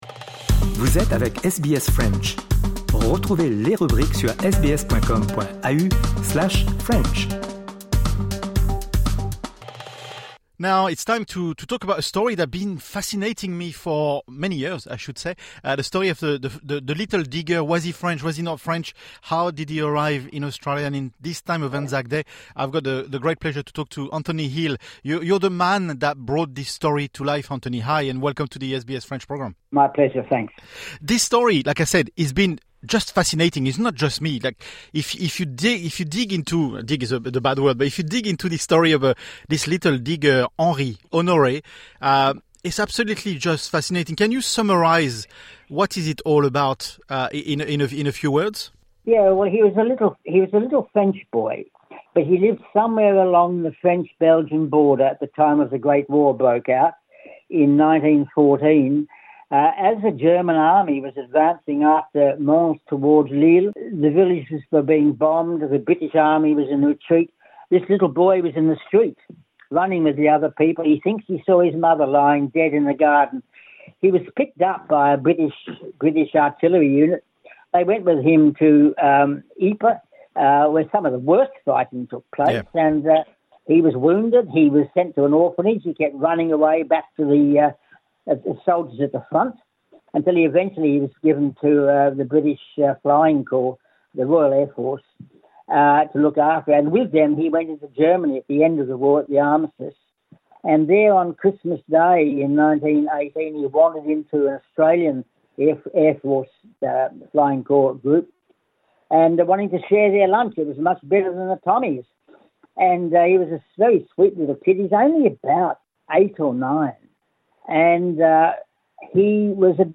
(Interview in English)